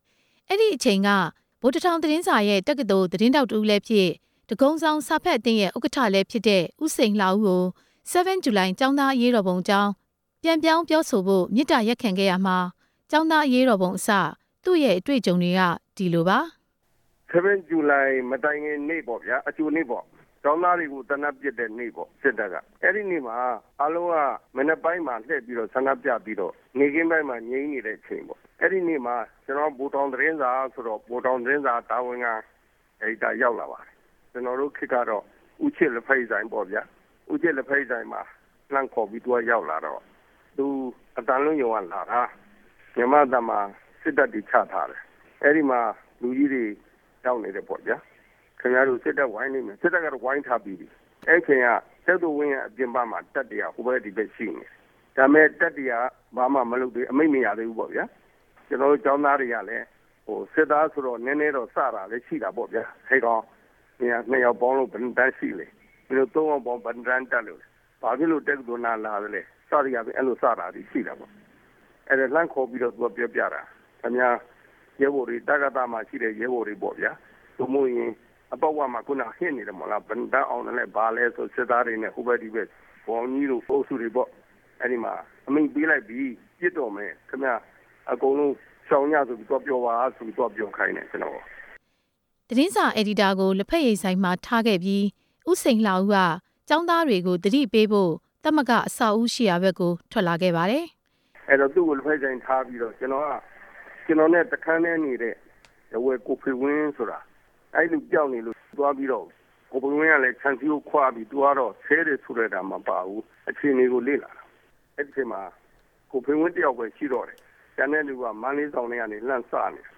၁၉၆၂ခု ဇူလိုင် ၇ ရက် ကျောင်းသား အရေးတော်ပုံ ကိုယ်တွေ့ ကြုံခဲ့သူများရဲ့ ပြောပြချက်